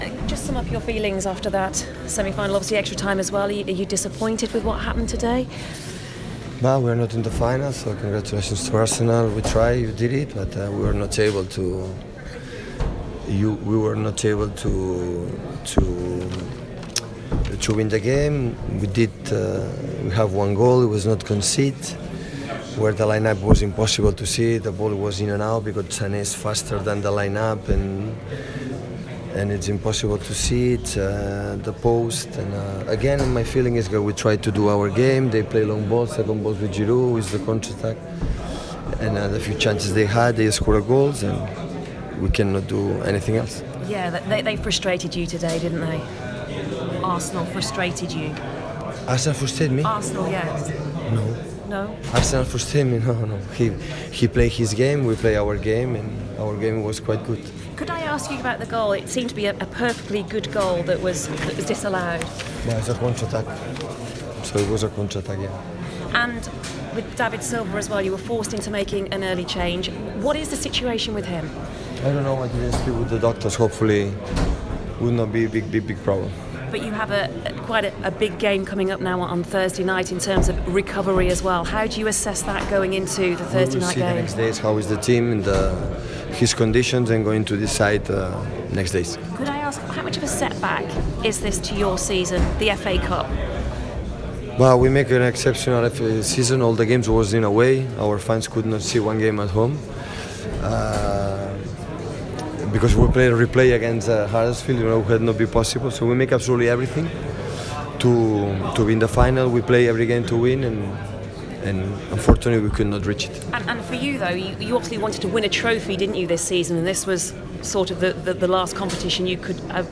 Manchester City boss Pep Guardiola has his say following FA Cup semi-final defeat at Wembley.